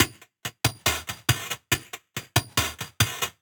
Gamer World Drum Loop 1.wav